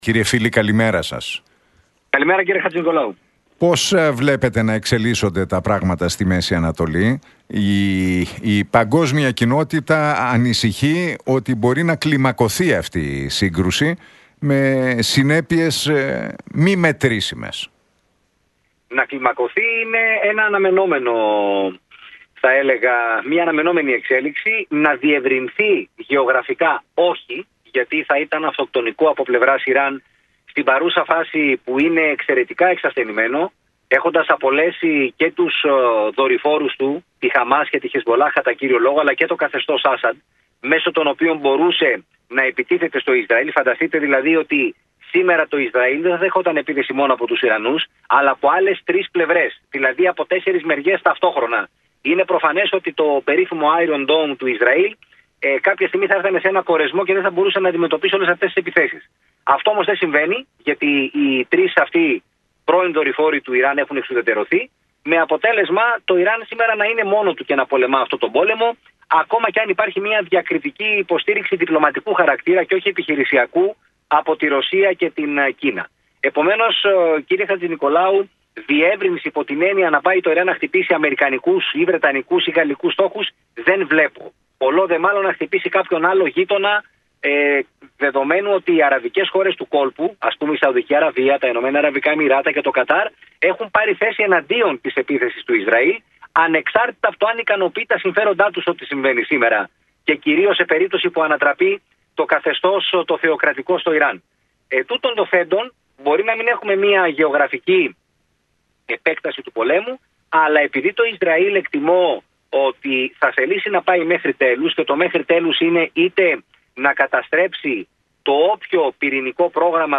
«Το να κλιμακωθεί η ένταση είναι μία αναμενόμενη εξέλιξη, να διευρυνθεί γεωγραφικά όχι» τόνισε ο διεθνολόγος, Κωνσταντίνος Φίλης στον Realfm 97,8 και τον Νίκο Χατζηνικολάου, αναφερόμενος στις εξελίξεις στην Μέση Ανατολή.